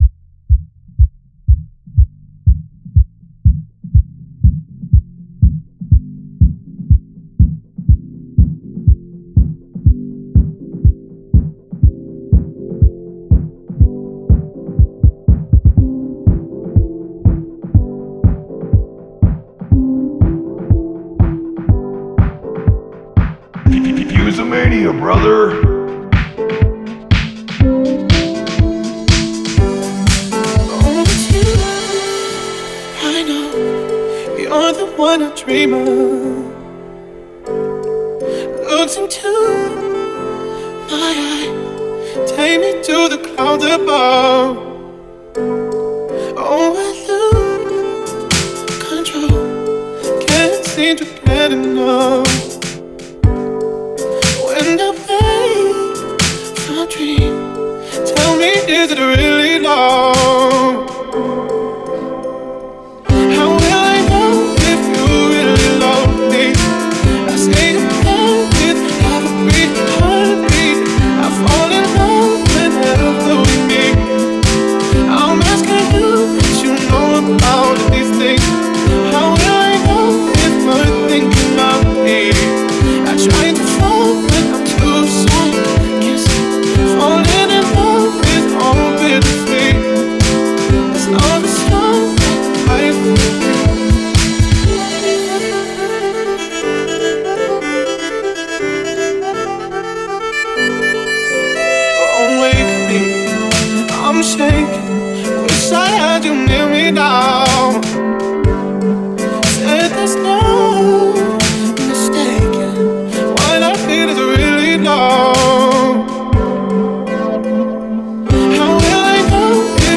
indie dance & house mix